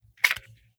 9mm Micro Pistol - Dropping Magazine 003.wav